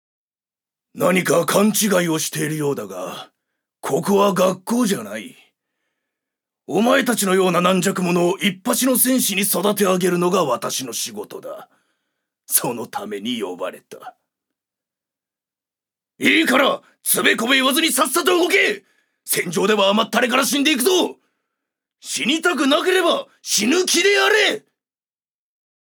所属：男性タレント
セリフ４